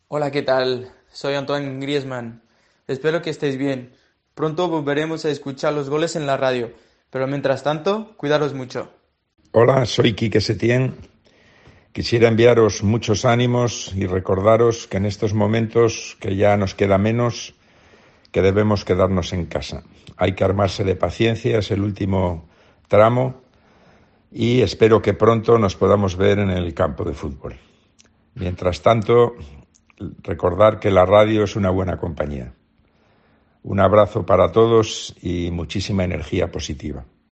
AUDIO: Los mensajes de Antoine Griezmann y Quique Setién para los oyentes de Tiempo de Juego.